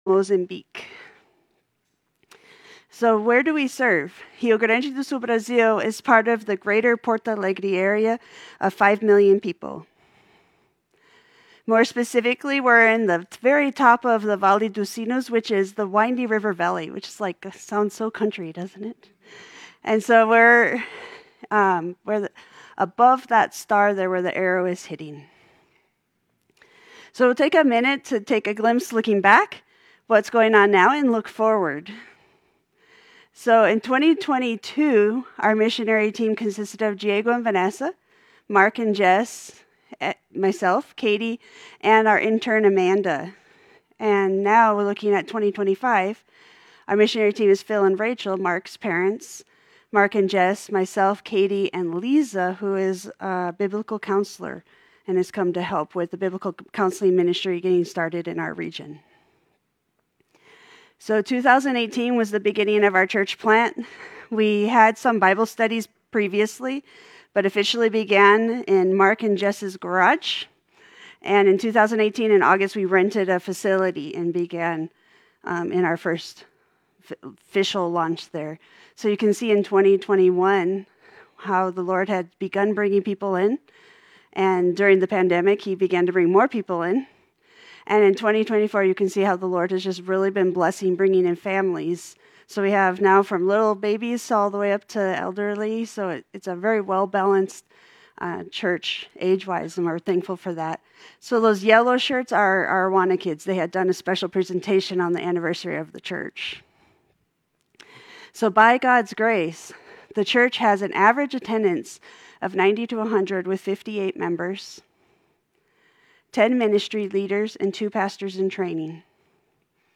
Missionary Update
Service Type: Sunday Studies